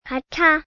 camera_shutter_chalkak_china.wav